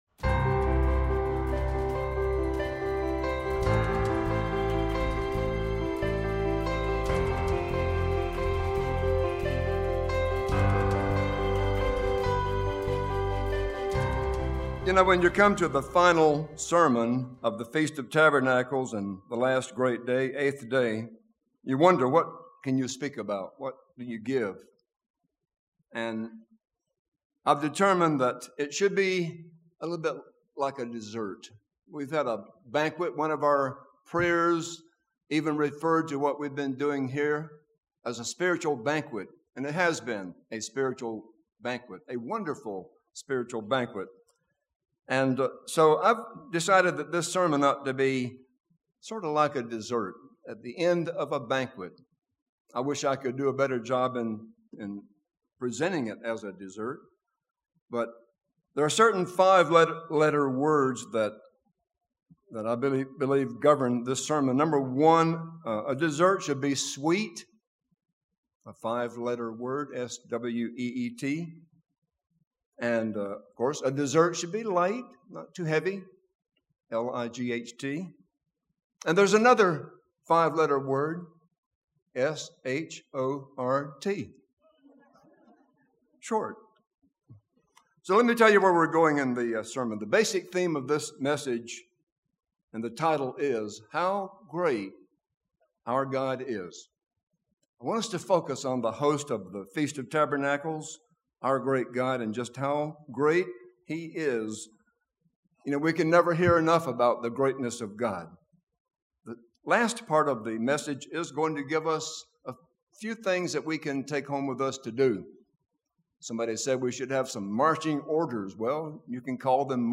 This sermon was given at the Lake Junaluska, North Carolina 2017 Feast site.